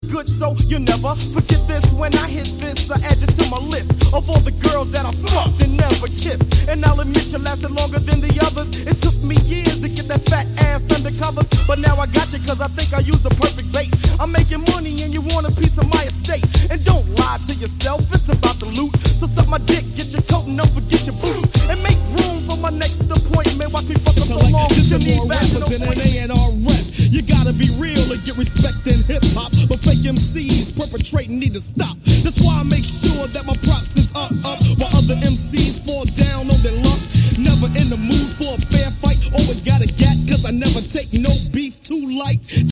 Mega rare tape version
hardcore hip-hop